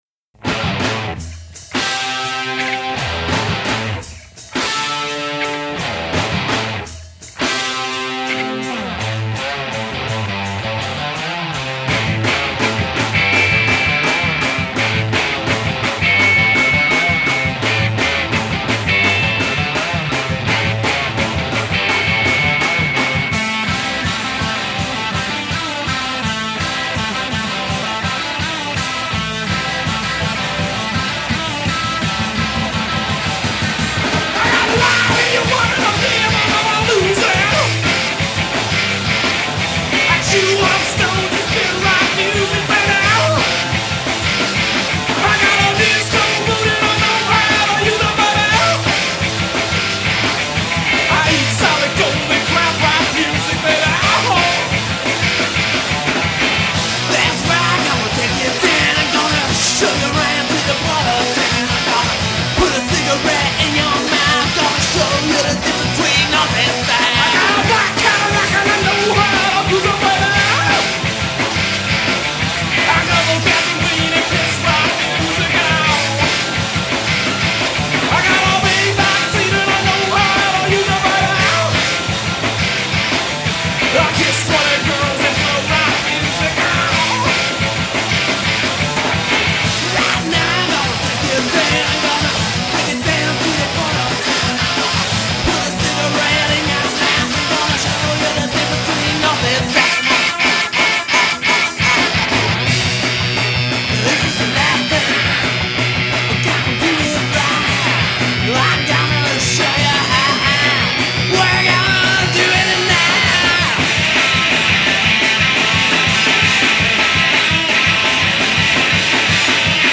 Blistering Texas rock and roll.